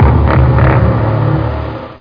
1 channel
bassoon2.mp3